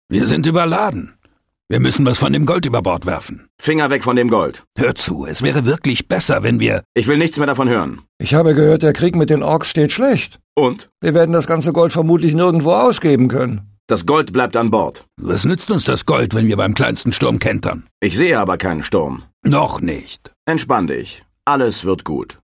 ich finde den abspann vomn gothic 2 gut, wo sich diego, der Held und gorn über das gold und das überladene schiff streiten.